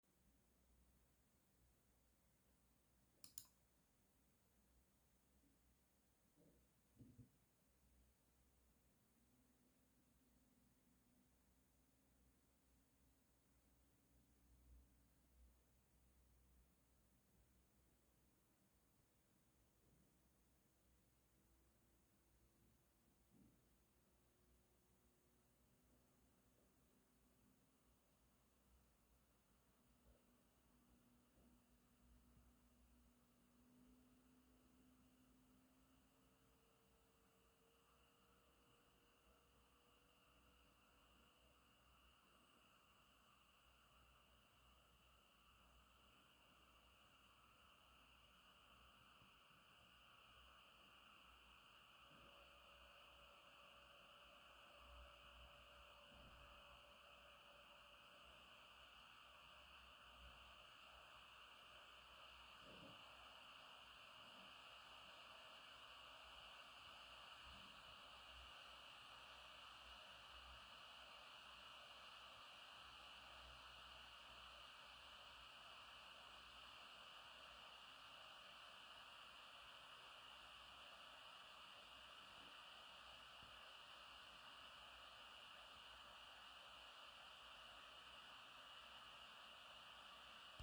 Das System lässt sich erst einmal ganz viel Zeit, bis der Lüfter dann aber stetig weiter aufdreht.
Weil die APU über 70 Watt aufnehmen darf, ist klar, dass das System mit 41 dB(A) gut hörbar kühlt.
Chuwi AuBox in der Standard-Einstellung